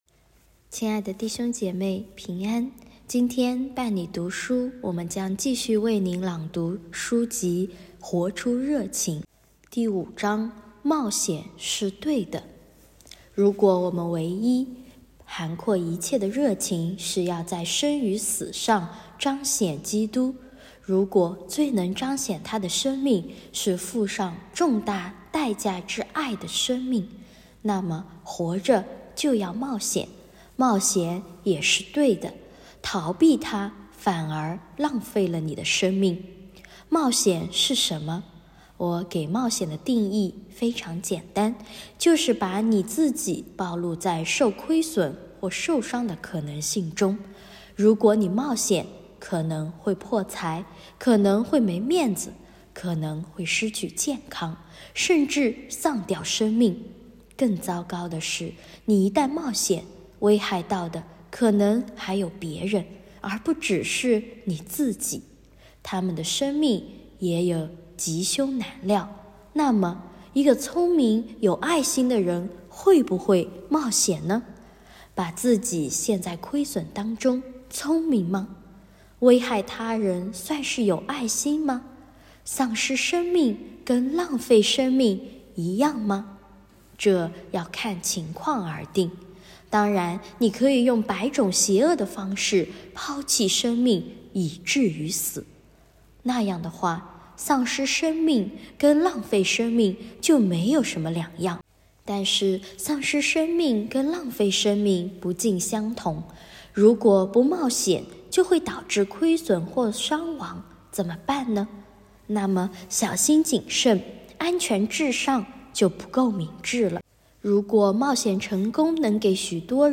2024年1月11日 “伴你读书”，正在为您朗读：《活出热情》 欢迎点击下方音频聆听朗读内容 音频 https